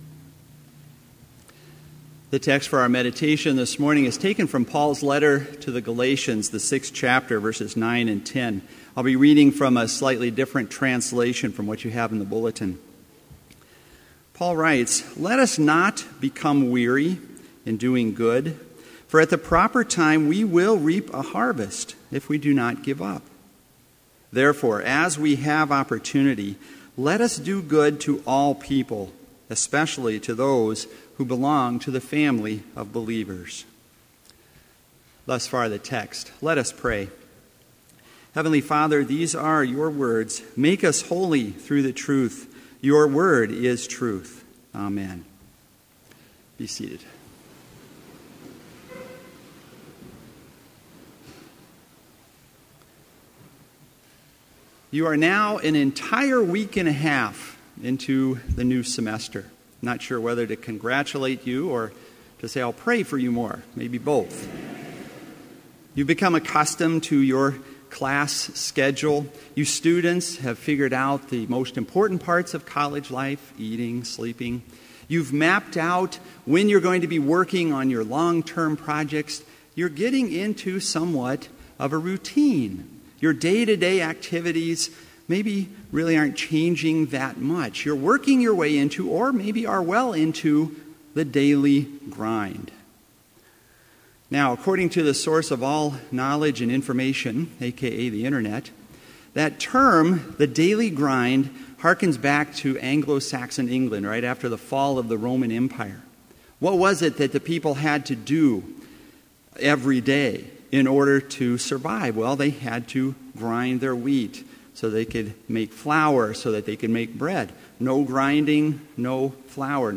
Complete Service
• Prelude
• Devotion
This Chapel Service was held in Trinity Chapel at Bethany Lutheran College on Thursday, September 3, 2015, at 10 a.m. Page and hymn numbers are from the Evangelical Lutheran Hymnary.